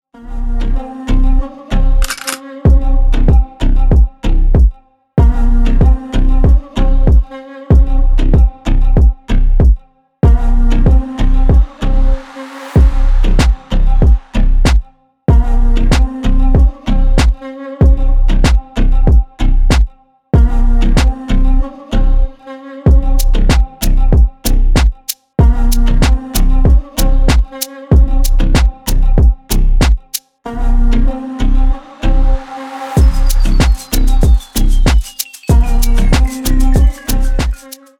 Танцевальные
клубные # громкие # без слов